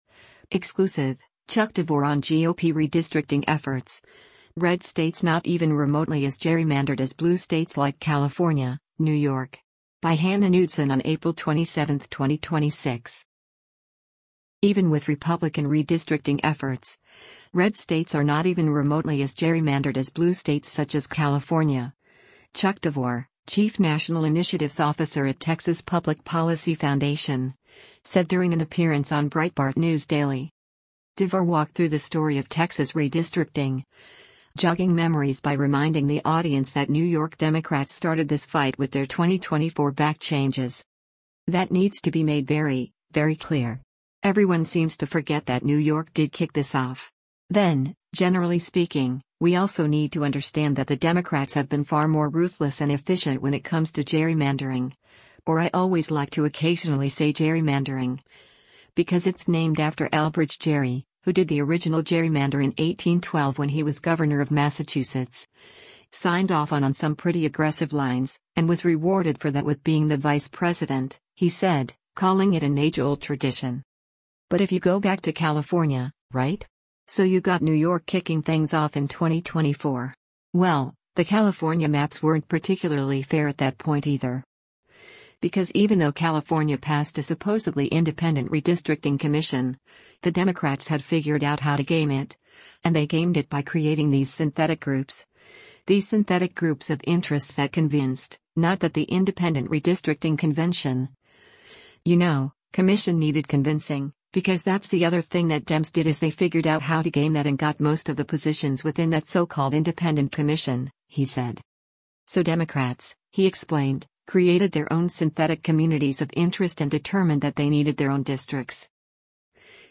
Even with Republican redistricting efforts, red states are “not even remotely as gerrymandered” as blue states such as California, Chuck Devore, Chief National Initiatives Officer at Texas Public Policy Foundation, said during an appearance on Breitbart News Daily.
Breitbart News Daily airs on SiriusXM Patriot 125 from 6:00 a.m. to 9:00 a.m. Eastern.